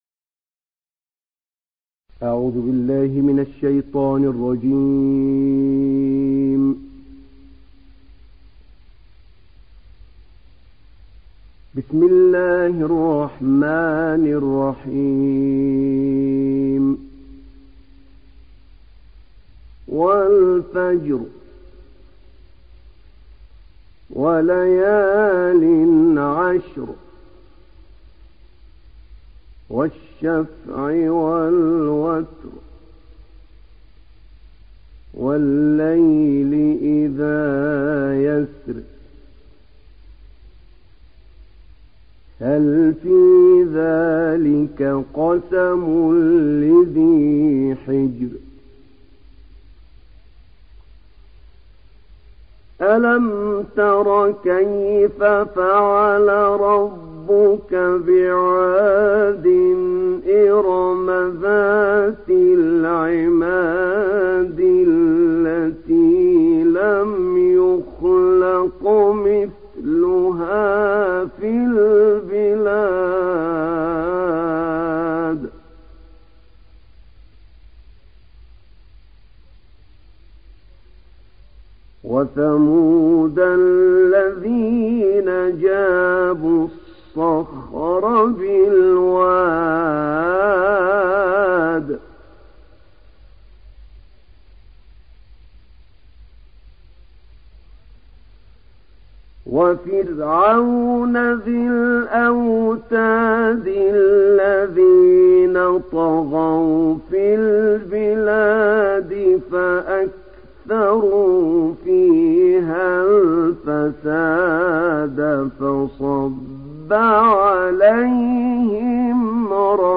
تحميل سورة الفجر mp3 بصوت أحمد نعينع برواية حفص عن عاصم, تحميل استماع القرآن الكريم على الجوال mp3 كاملا بروابط مباشرة وسريعة